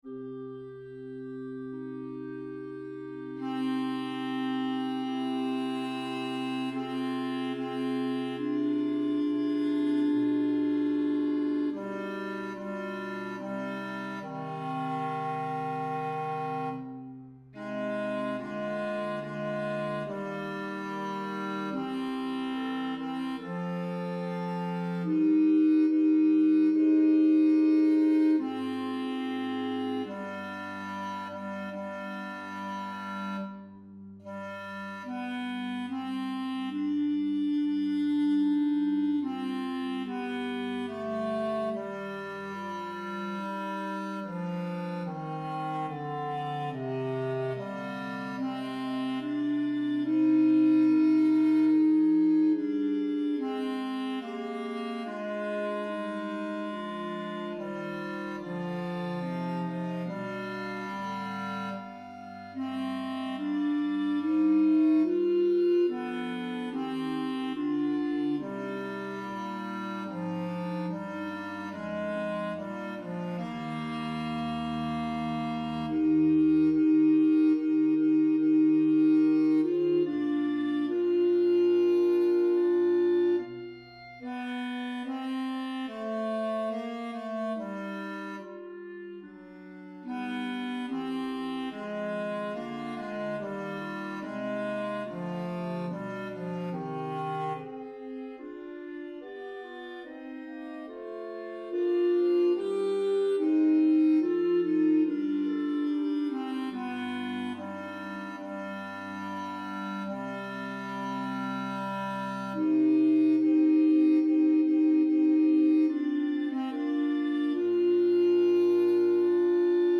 Man_that_is_born_of_a_woman_RR_Tenor.mp3